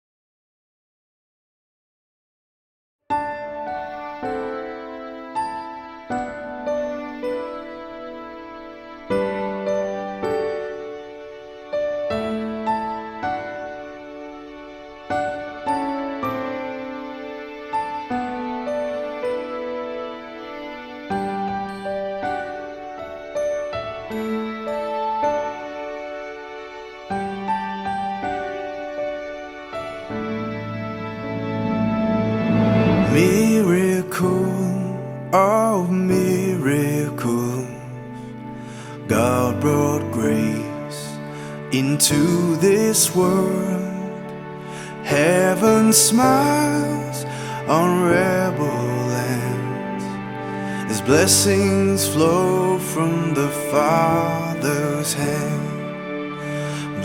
Christian Music